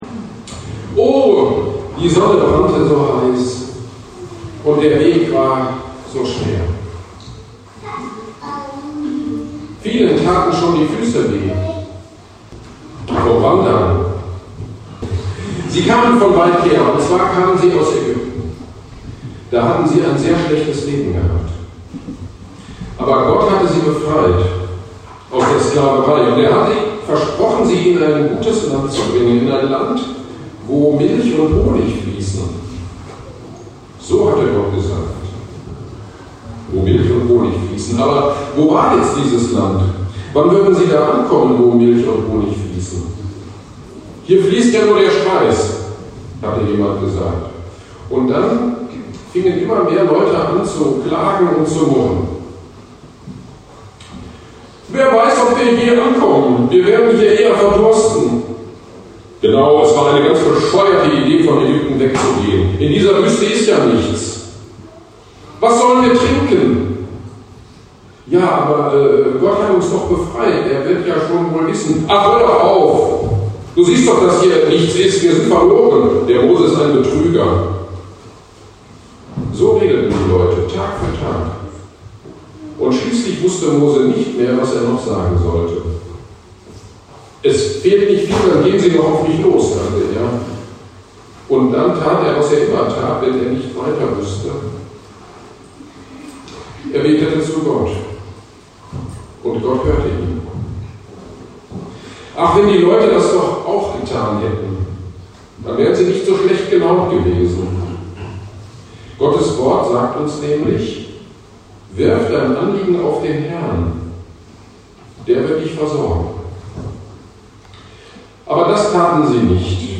25.09.22 Fam-GD zum Erntedank – Predigt zu 2. Mose 16